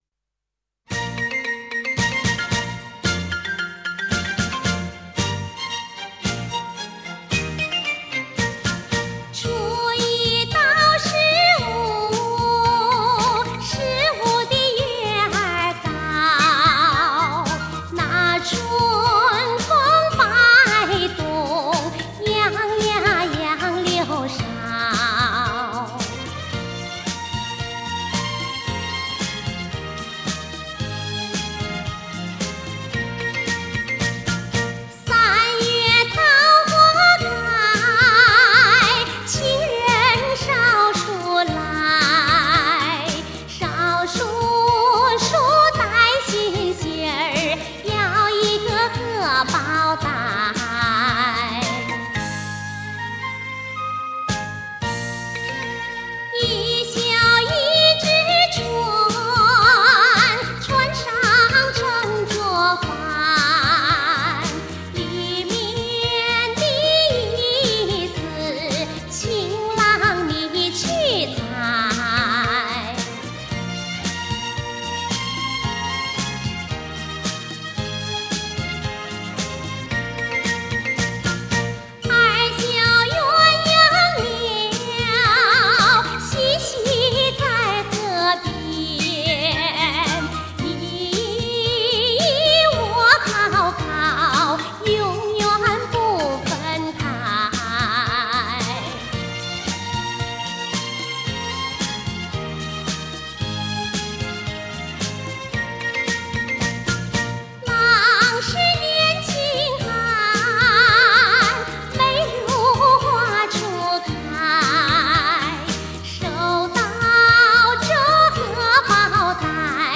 [12/6/2007]山西民歌《绣荷包》女声版 激动社区，陪你一起慢慢变老！